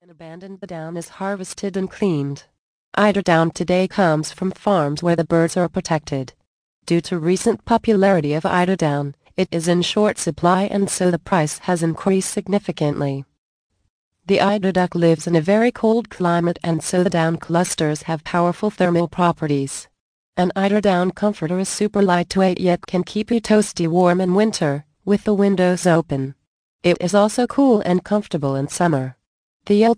The Magic of Sleep audio book Vol. 4 of 14, 68 min.